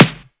• Short Snare Drum Sound F Key 334.wav
Royality free acoustic snare sample tuned to the F note. Loudest frequency: 3374Hz
short-snare-drum-sound-f-key-334-Uvh.wav